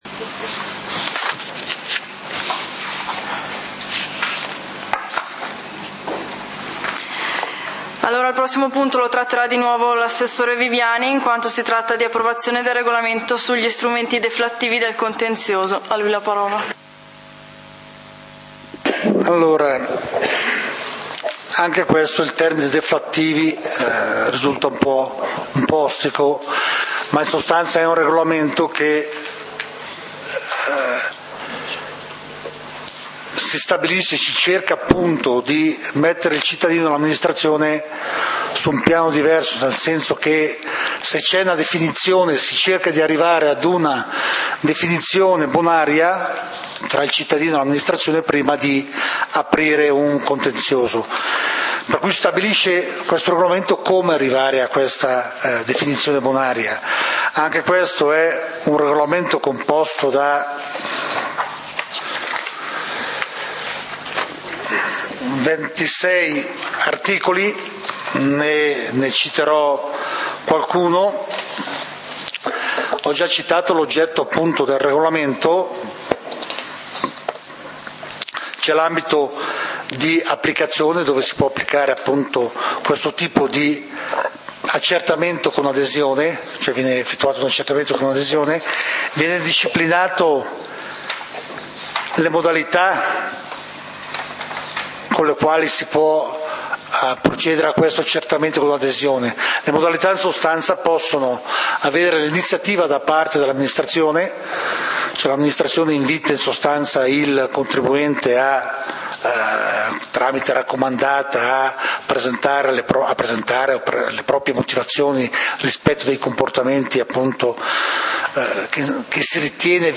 Punti del consiglio comunale di Valdidentro del 30 Ottobre 2012